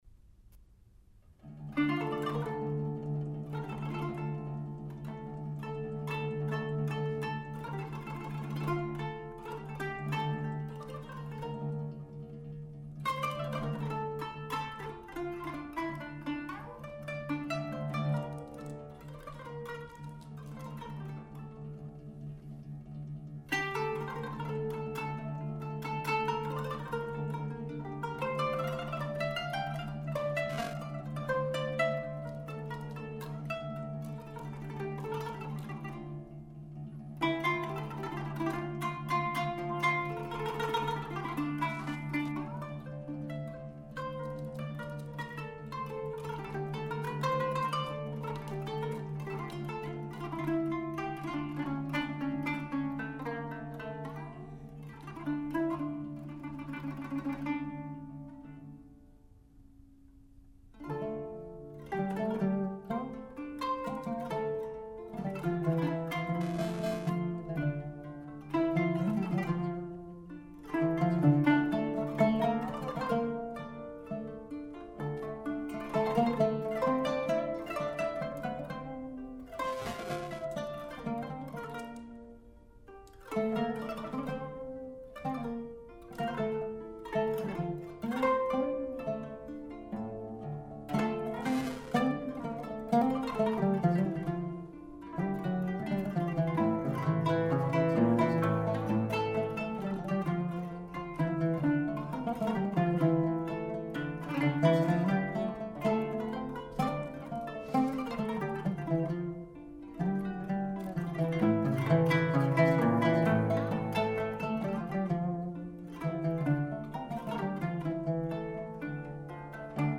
kanun
oud